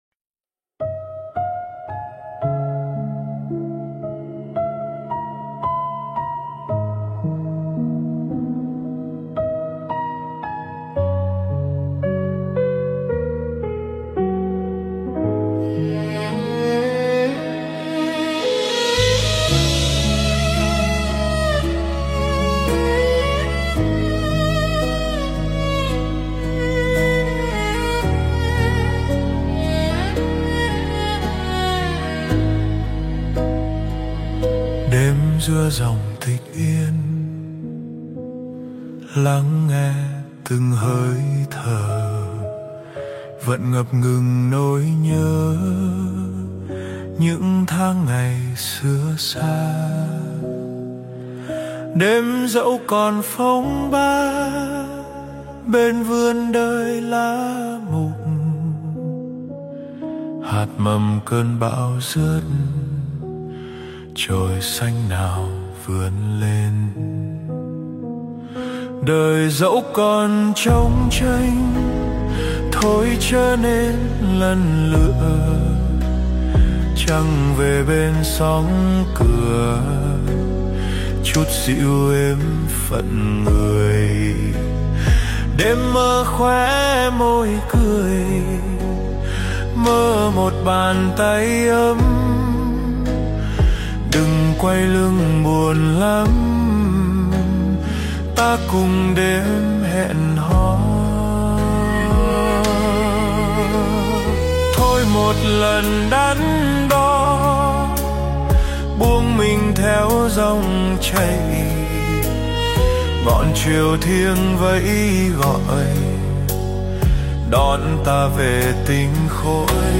511. Hát Kinh Nhật Tụng Vị Lai Pháp - Vị Lai Pháp
Phổ nhạc: Suno AI